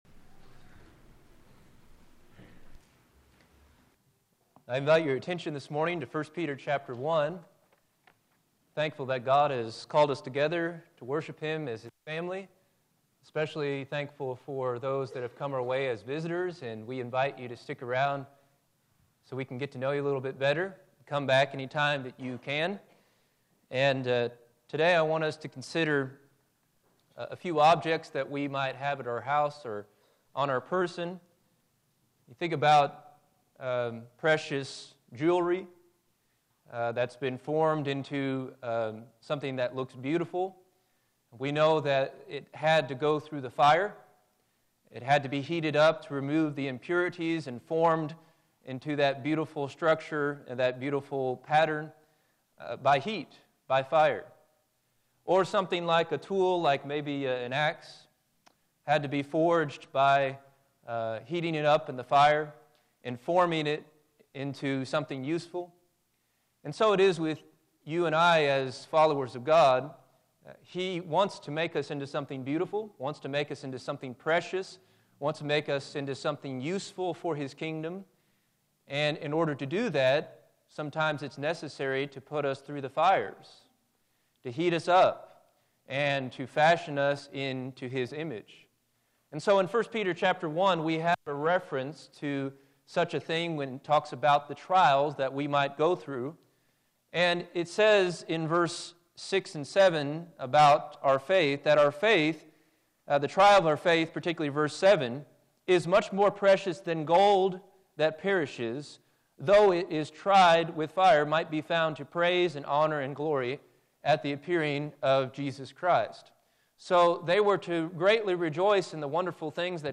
Filed Under: Featured, Lesson Audio